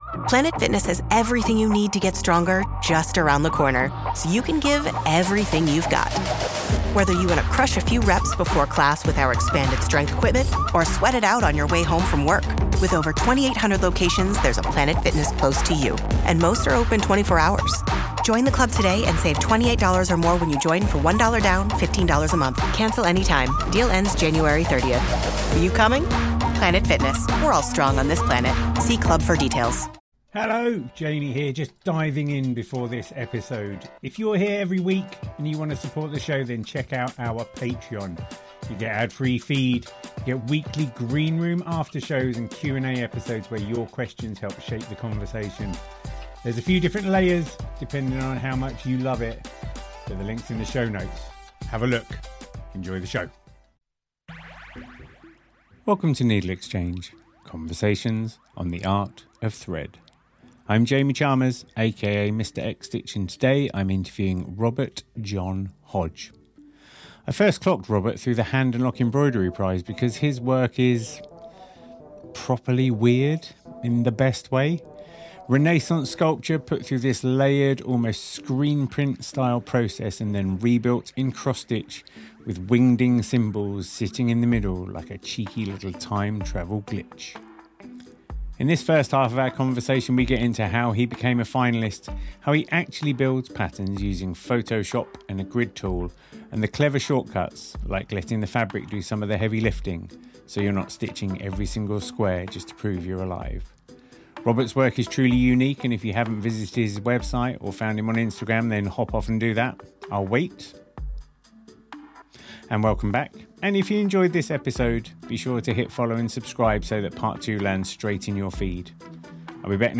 About NeedleXChange An artist interview podcast exploring contemporary embroidery and textile art .